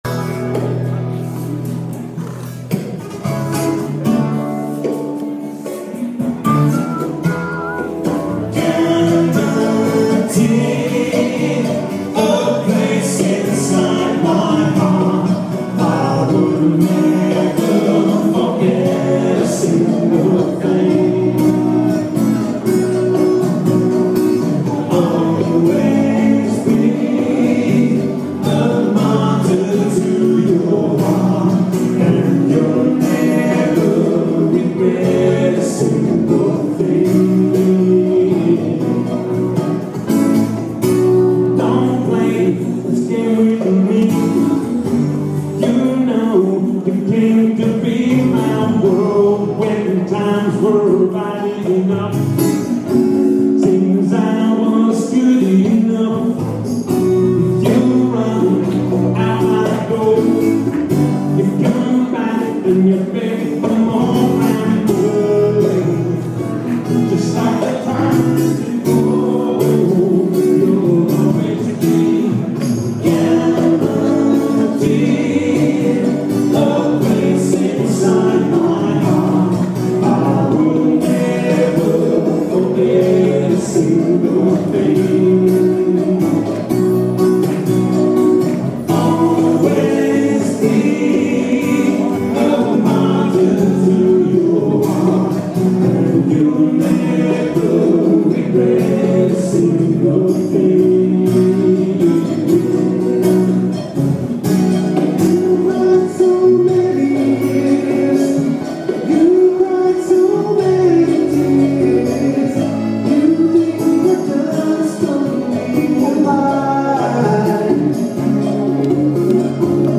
totally acoustic.